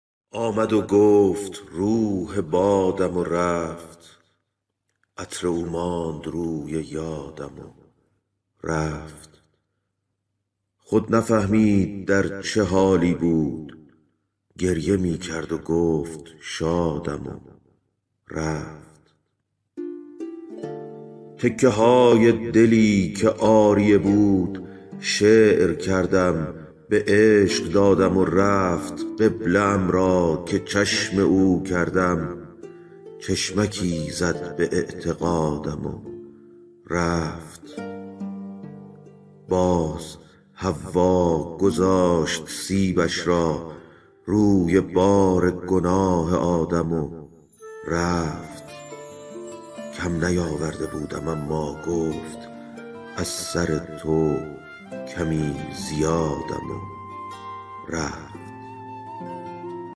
دانلود دکلمه آمدو گفت روح بادم و رفت با صدای افشین یداللهی
گوینده :   [افشین یداللهی]